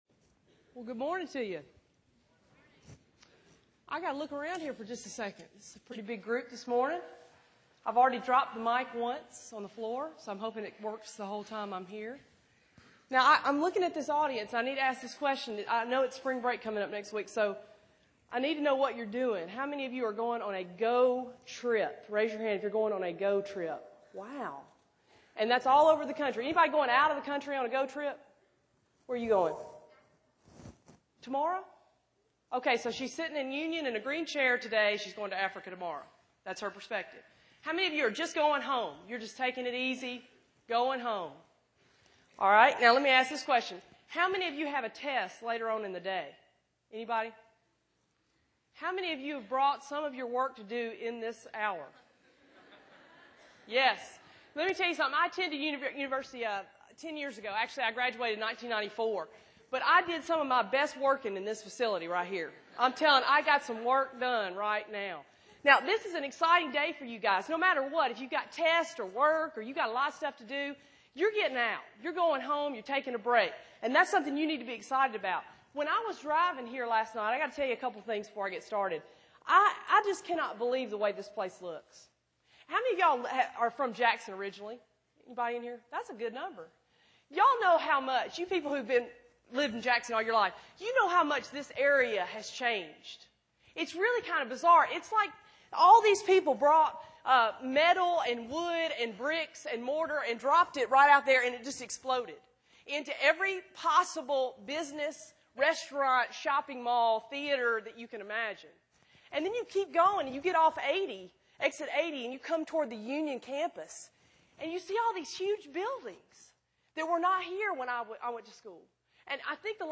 Union University, a Christian College in Tennessee